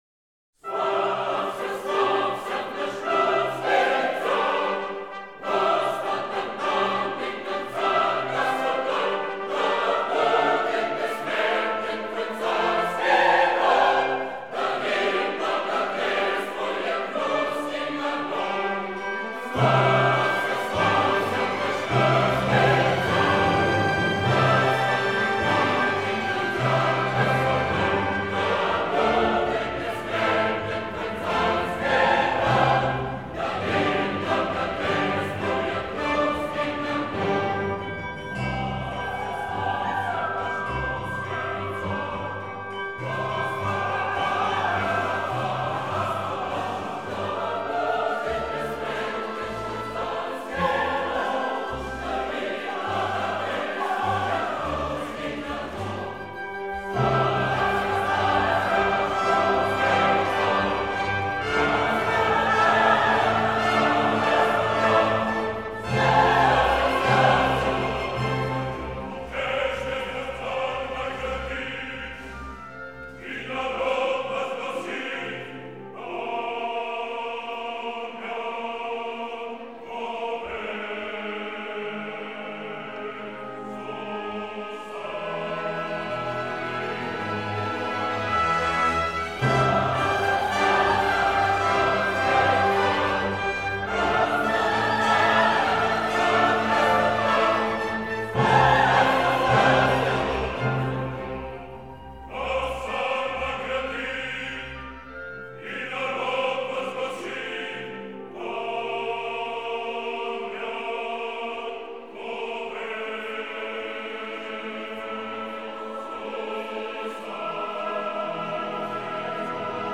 An Opera in Four Acts & Epilogue
Russian and Polish choruses, soldiers and people